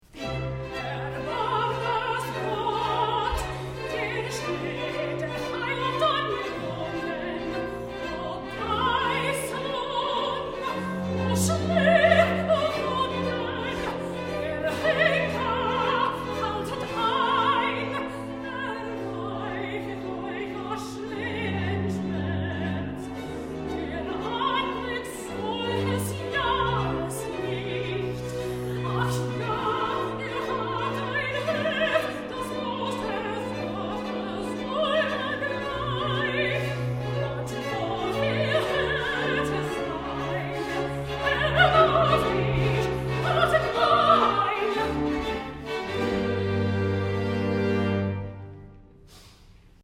Bach alto arias